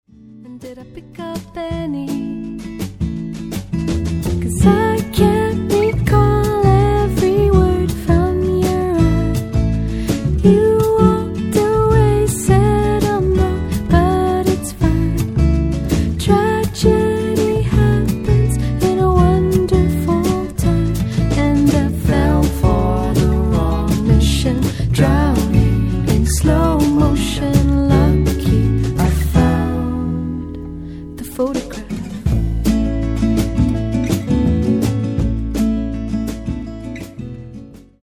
インディポップ/ワールド